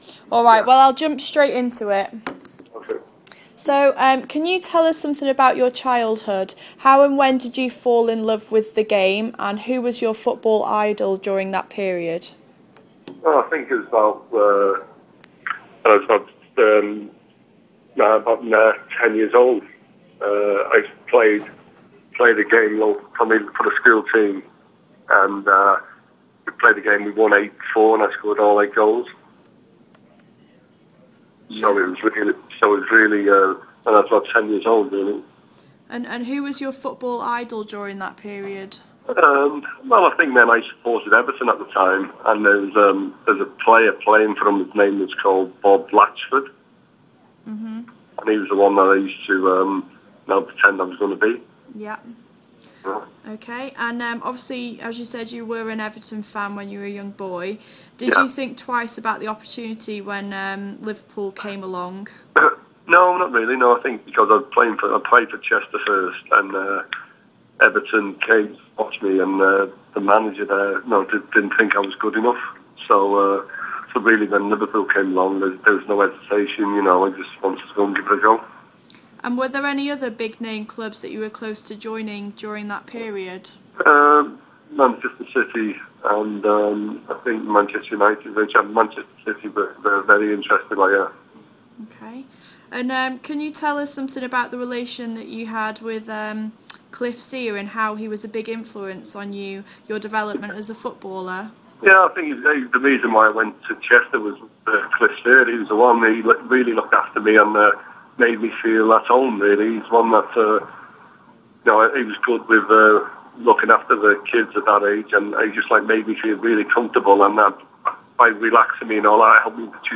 Liverpool FC - Ian Rush | Exclusive Interview